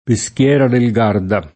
peschiera [peSkL$ra] s. f. («laghetto; vivaio») — sim. il top. P. (es.: Peschiera del Garda [